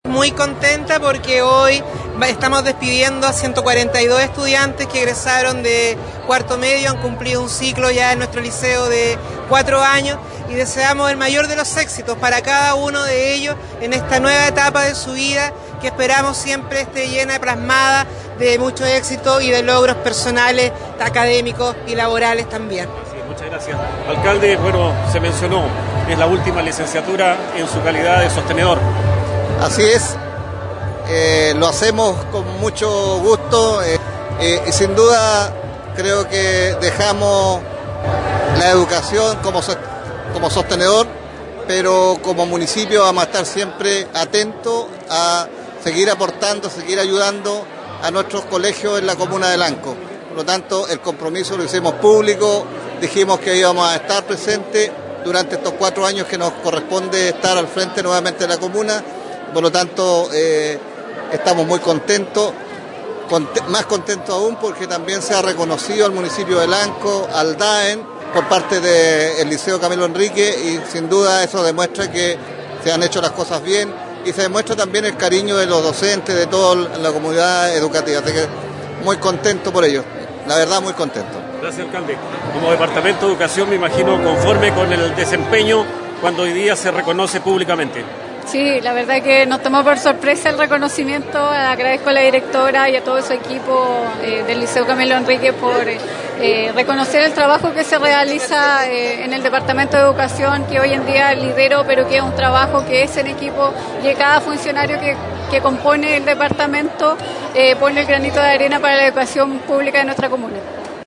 Con una solemne ceremonia realizada en el gimnasio del establecimiento, el Liceo Bicentenario Camilo Henríquez González de Lanco celebró la licenciatura de 142 estudiantes que egresaron de la educación media.